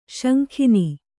♪ śankhini